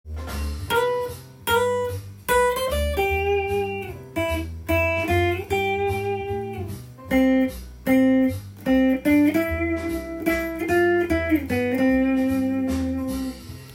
jazz系のソロだとこんな雰囲気になり
jazz.utau_.m4a